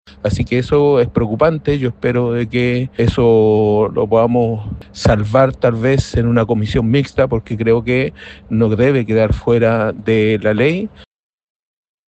En tanto, el diputado del Partido Comunista y presidente de la Comisión de Hacienda, Boris Barrera, expresó su preocupación por la eliminación de esos dos puntos.